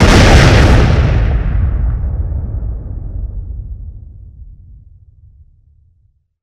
explosion_single_large_01
Tags: Sci Fi Play